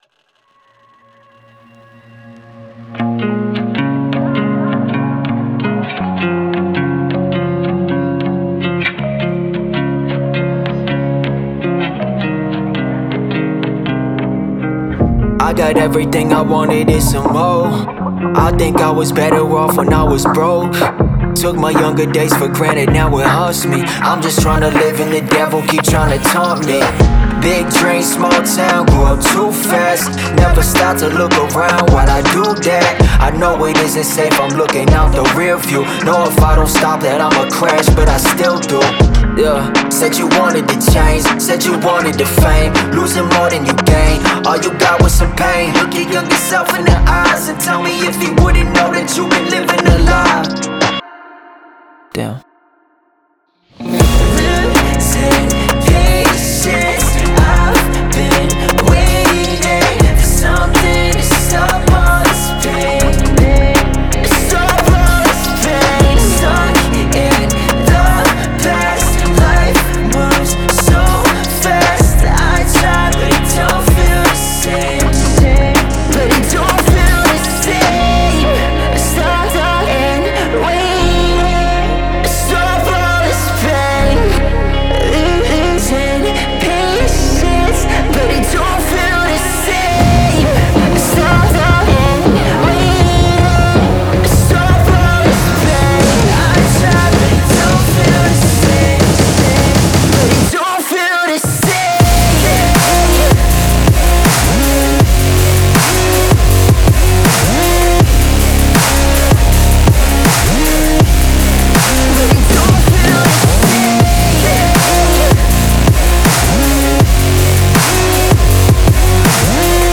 эмоциональная электронная композиция